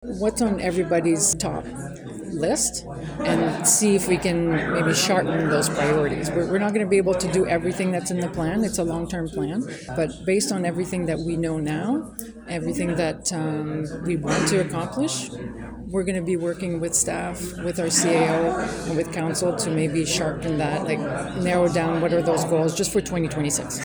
In an interview with myFM afterward, she emphasized the importance of thoughtful leadership on key priorities and promised a year of stability and clarity.